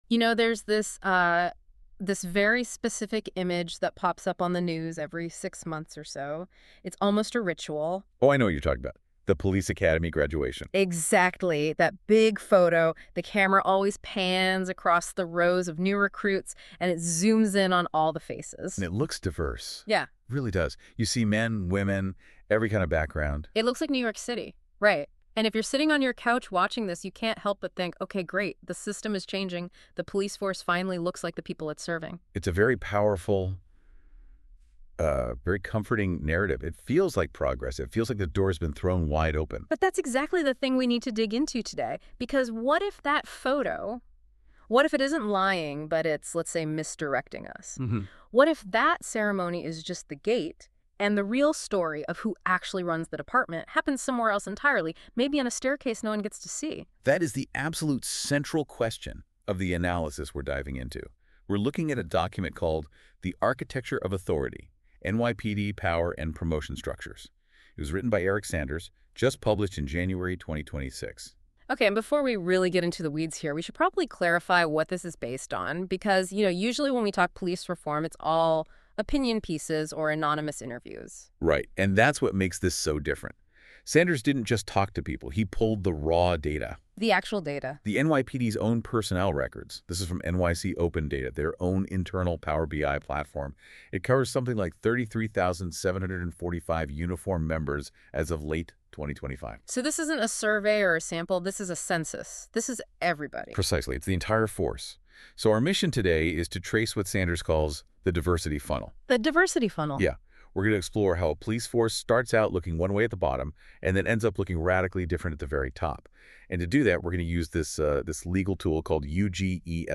Second, a Deep-Dive Podcast that expands on the analysis in conversational form. The podcast explores the historical context, legal doctrine, and real-world consequences in greater depth, including areas that benefit from narrative explanation rather than footnotes.